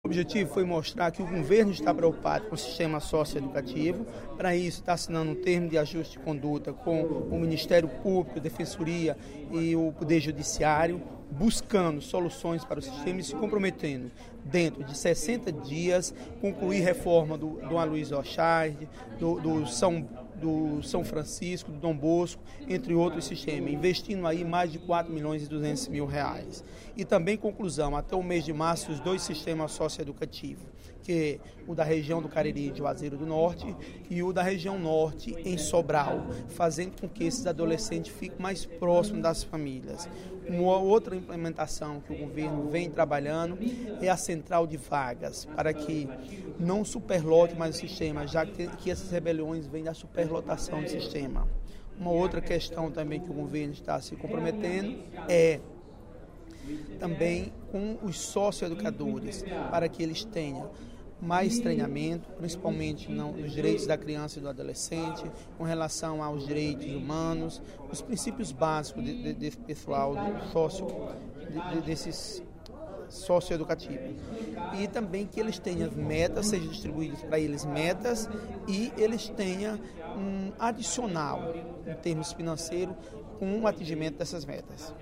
O deputado Zé Ailton Brasil (PP) afirmou, nesta terça-feira (17/11), no primeiro expediente da sessão plenária da Assembleia Legislativa, que o Governo do Estado tem se mostrado preocupado com as unidades socioeducativas.